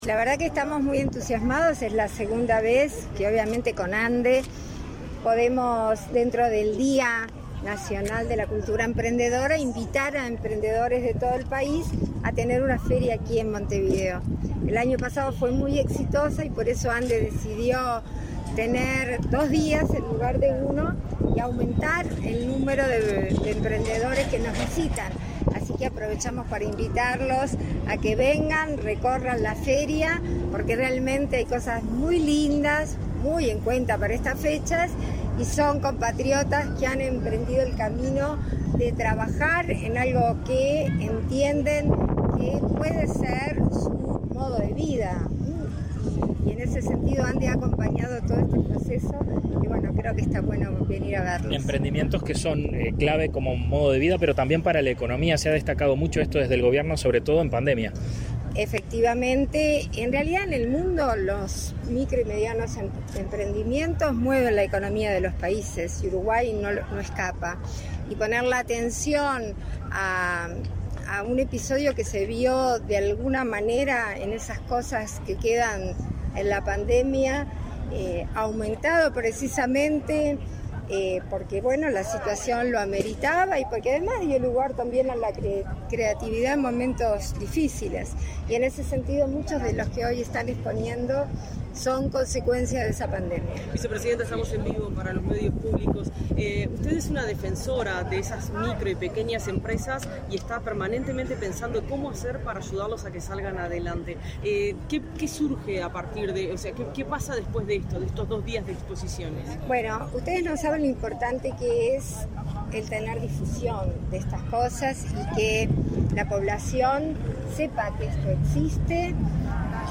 Declaraciones de prensa de la vicepresidenta de la República, Beatríz Argimon
Este 18 de noviembre se realizó la segunda edición de Espacio Emprendedor, una feria de emprendimientos organizada por la Agencia Nacional de Desarrollo (ANDE) y el Parlamento, en el marco del Día Nacional de la Cultura Emprendedora. En la oportunidad, la vicepresidenta de la República realizó declaraciones a la prensa.